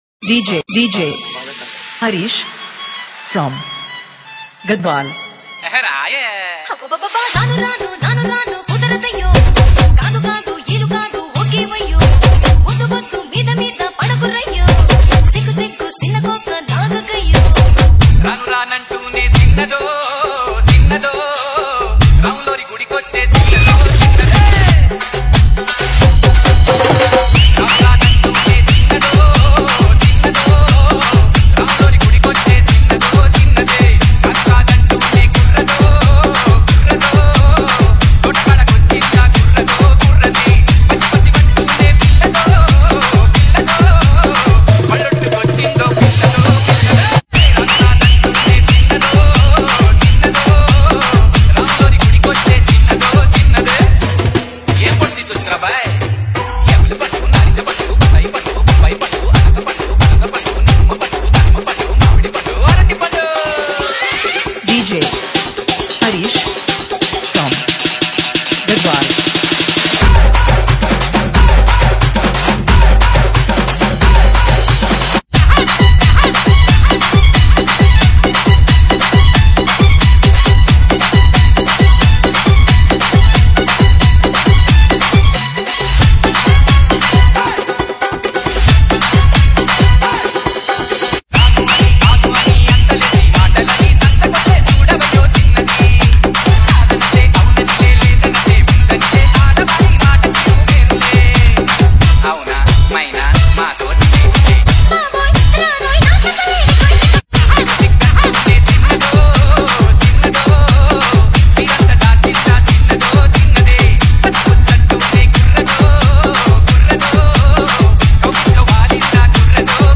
TELUGU MOVI DJ REMIX